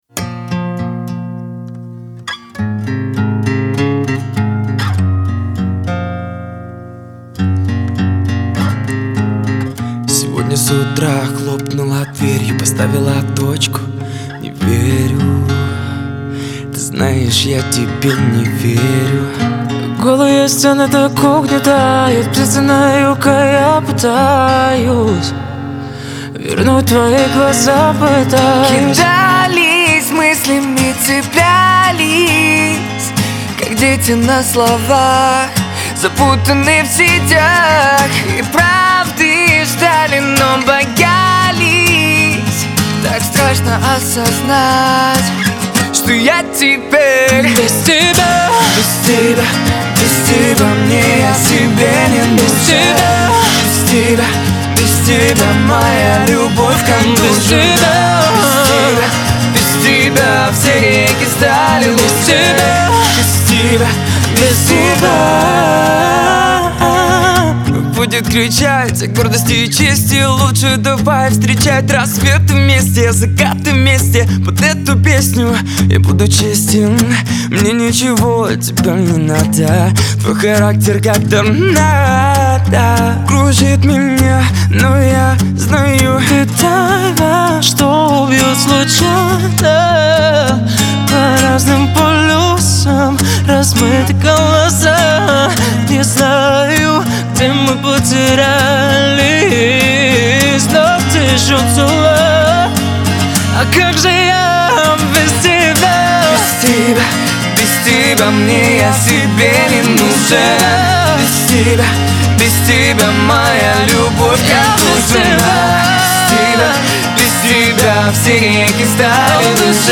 это проникновенная акустическая баллада в жанре поп-рок.